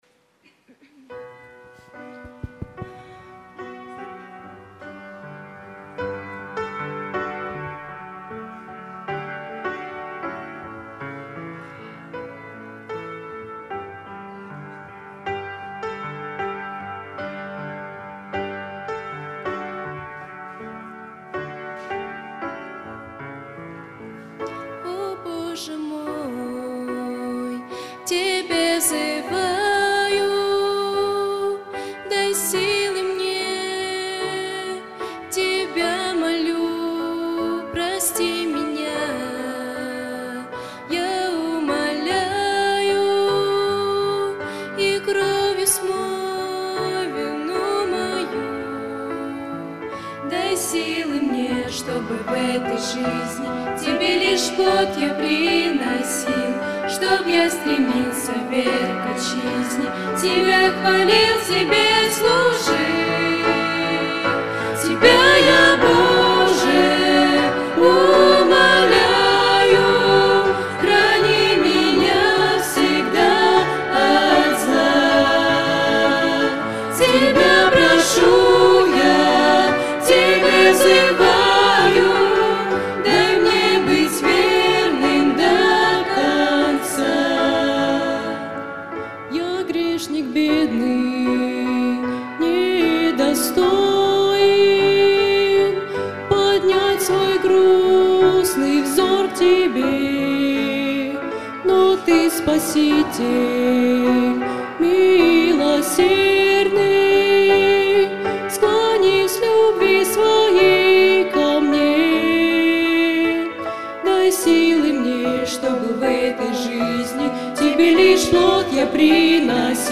Богослужение 06.10.2024
О, Боже мой, к Тебе взываю - Сестры (Пение)[